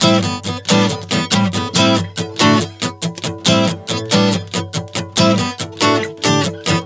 Strum 140 Am 06.wav